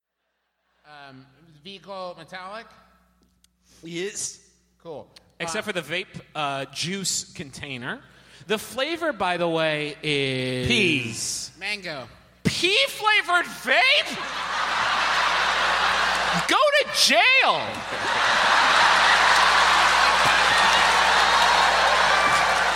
moment from live show of The Adventure Zone, released on